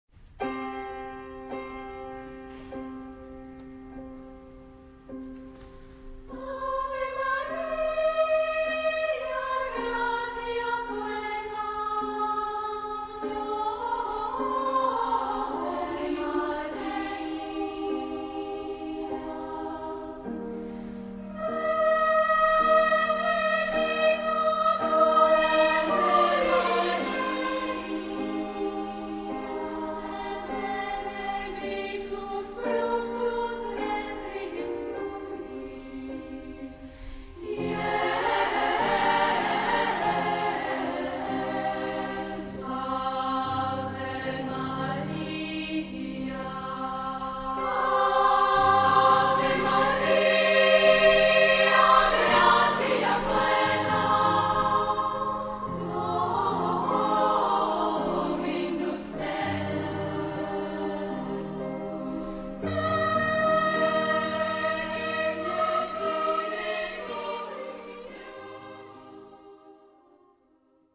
Género/Estilo/Forma: Sagrado ; Plegaria
Tipo de formación coral: SSA  (3 voces Coro femenino )
Solistas : Soprano (1)  (1 solista(s) )
Instrumentos: Piano (1)
Tonalidad : do menor